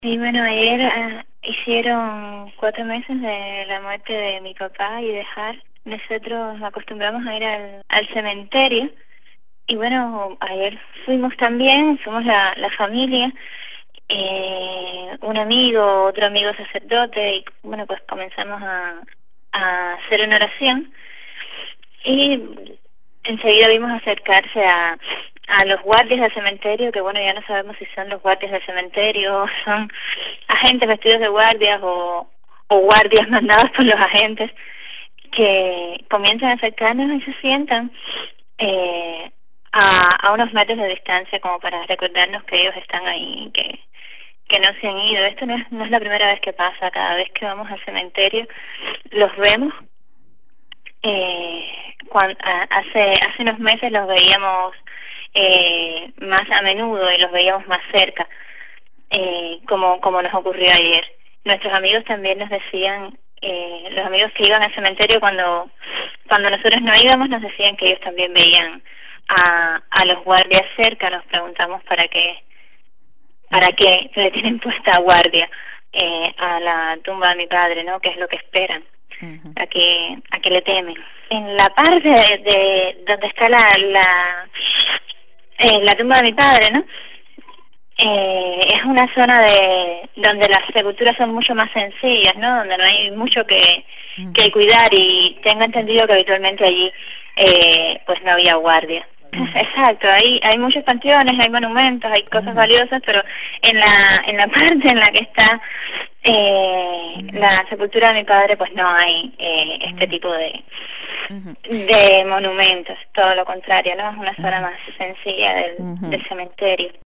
Declaraciones íntegras